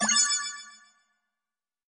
get_coin1.mp3